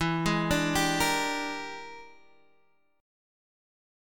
E7#9b5 chord